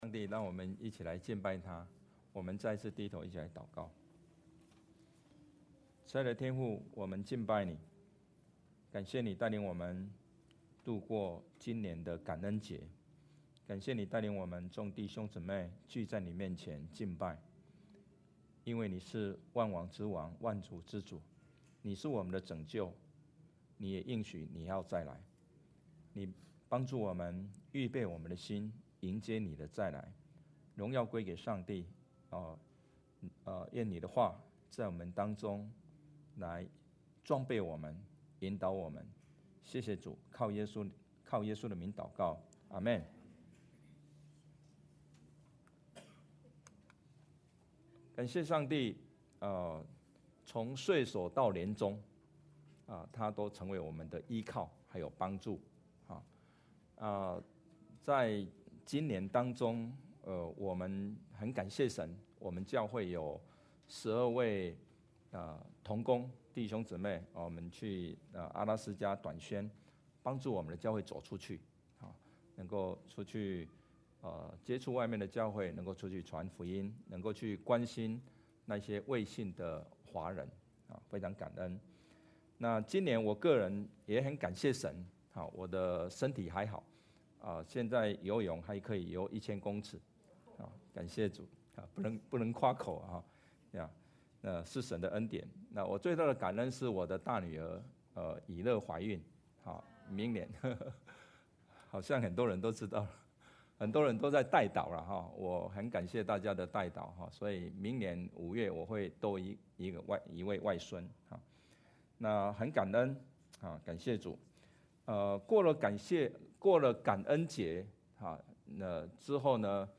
主日证道 Download Files Notes « 感恩神助 黑暗中的光 » Submit a Comment Cancel reply Your email address will not be published.